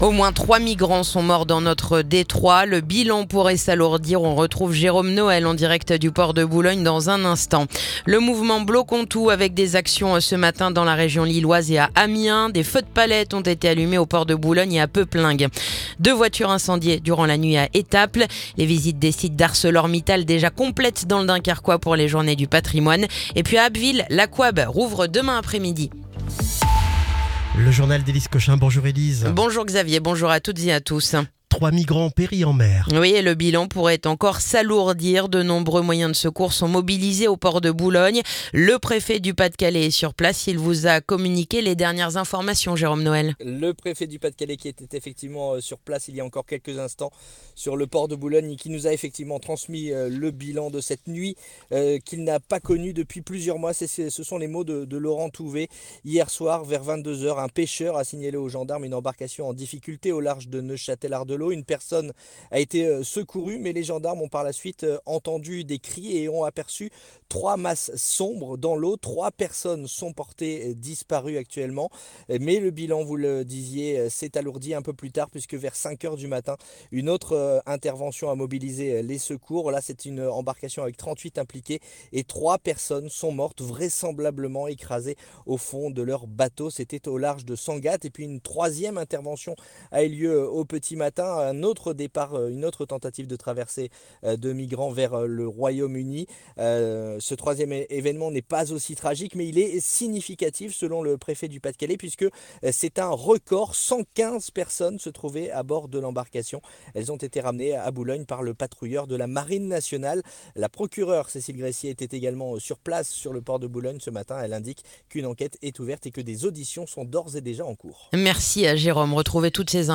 Le journal du mercredi 10 septembre